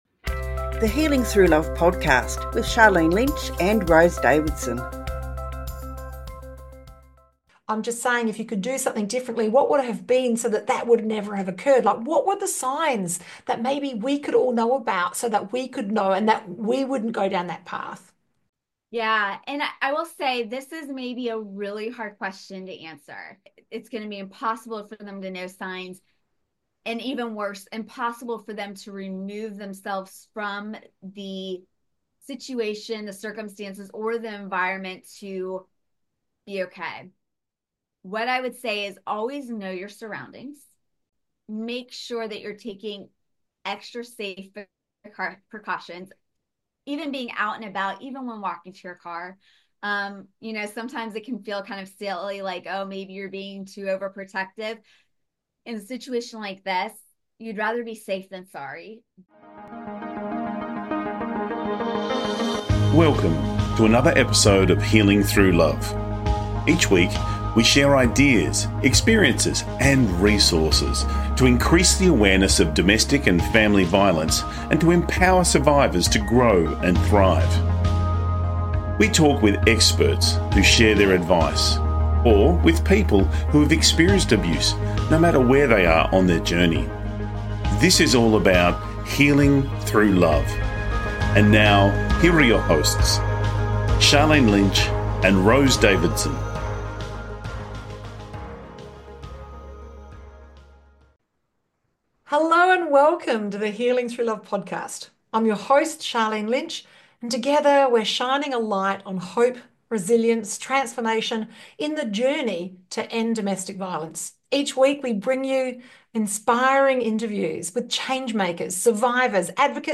Key Points from the Interview: Self-Advocacy in the Legal System – How to stand up for yourself and navigate judicial injustices.